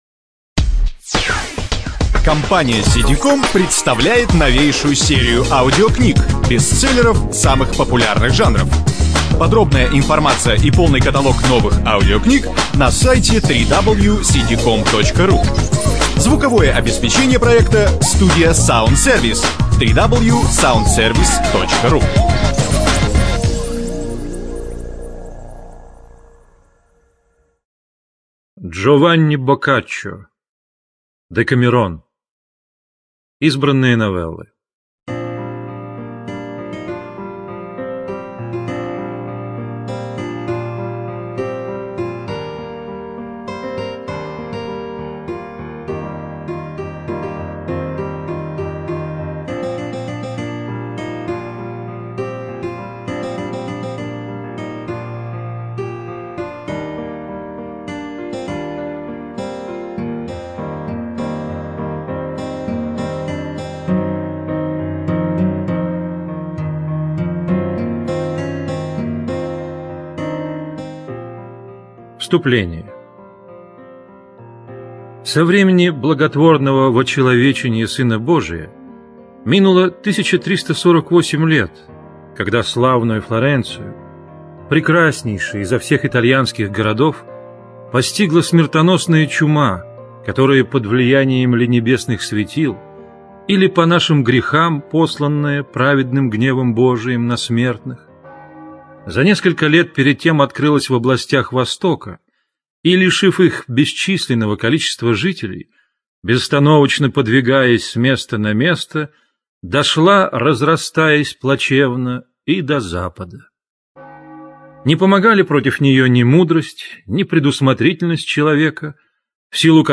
ЖанрКлассическая проза
Студия звукозаписиСидиком